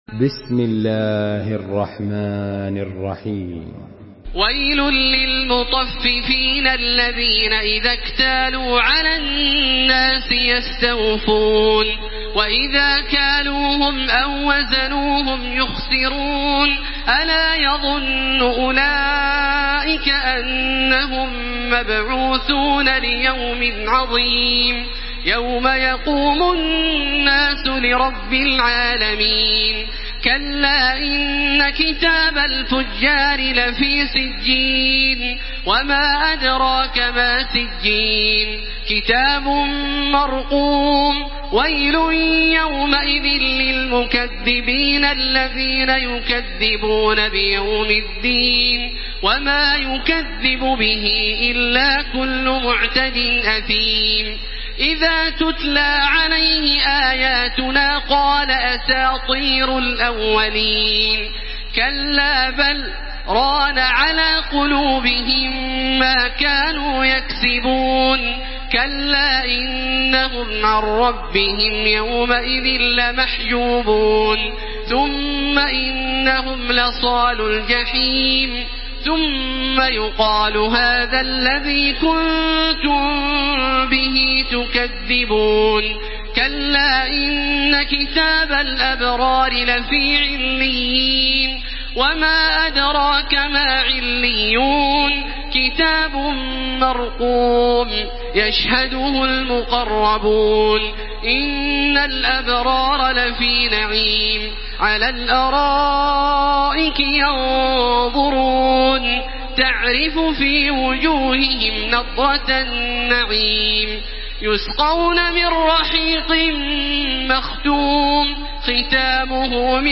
Surah Al-Mutaffifin MP3 in the Voice of Makkah Taraweeh 1434 in Hafs Narration
Murattal Hafs An Asim